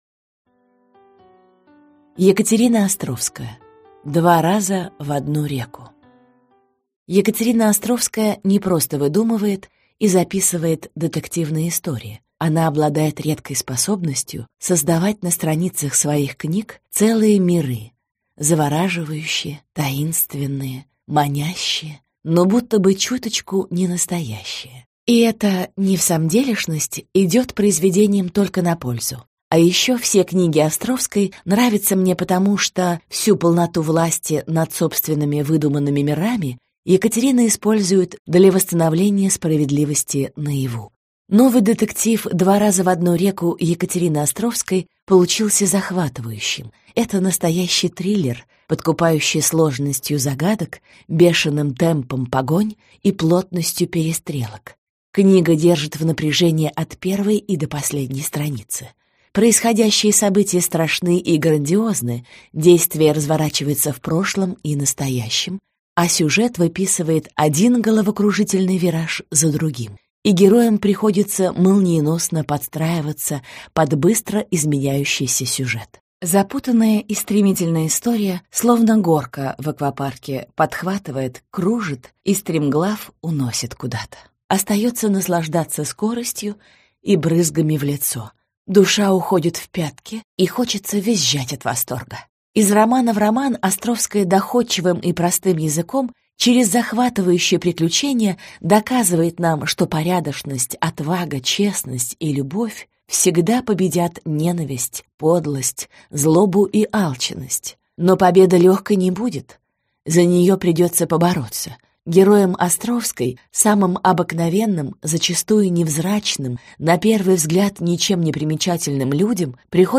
Аудиокнига Два раза в одну реку | Библиотека аудиокниг